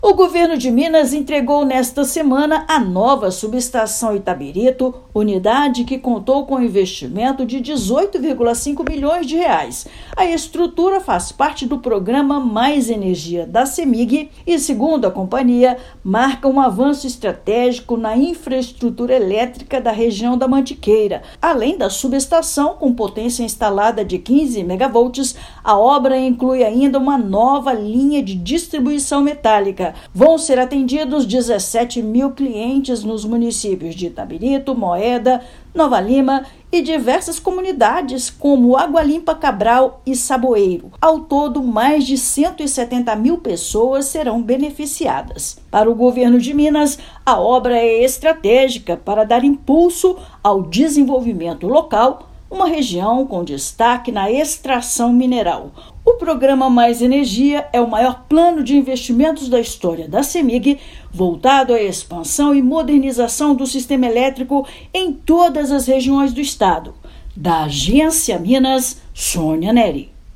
Estrutura contou com investimento de R$ 18,5 milhões e irá beneficiar aproximadamente 170 mil pessoas. Ouça matéria de rádio.